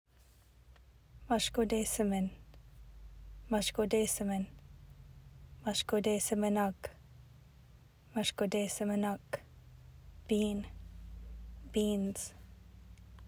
Anishinaabemowin pronunciation: "mush-ko-day-si-min (ug)"